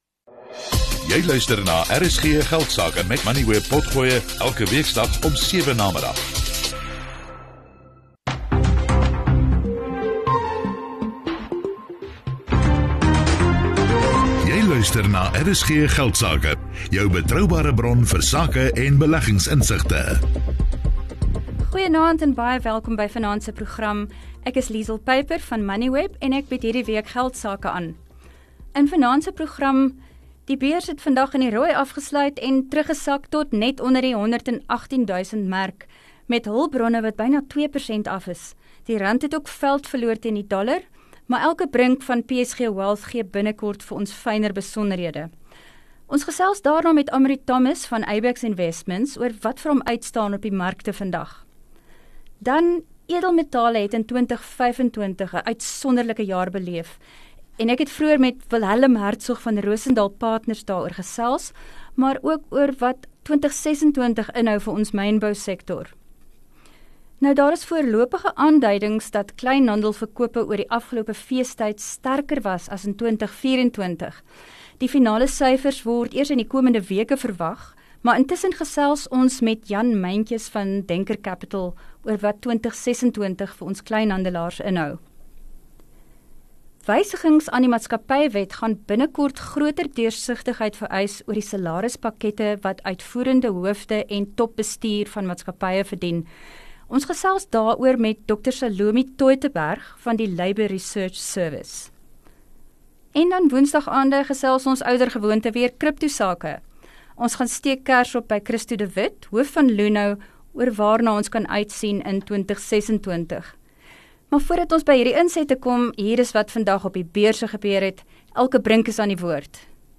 Kenners antwoord luisteraarsvrae. Marble is vol op Valentynsaand.
Dit fokus op belangrike sakenuus, menings en beleggingsinsigte. RSG Geldsake word elke weeksdag tussen 18:10 en 19:00 op RSG (101 – 104 FM) uitgesaai.